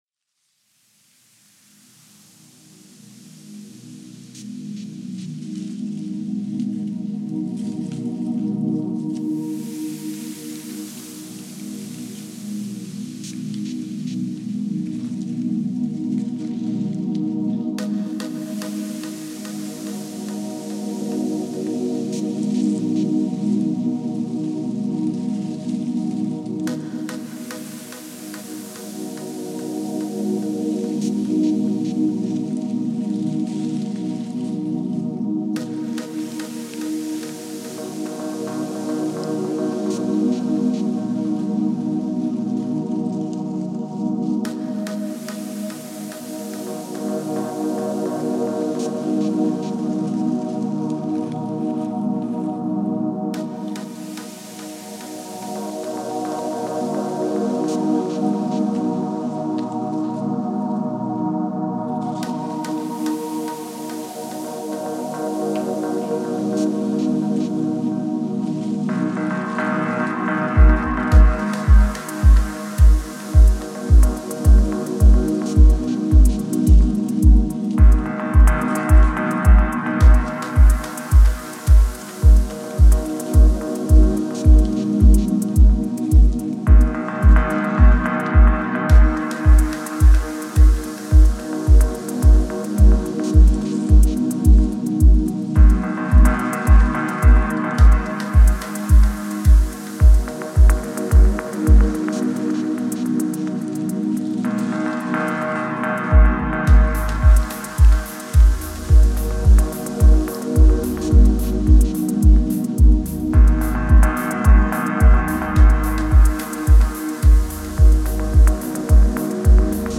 Genre: Dub Techno.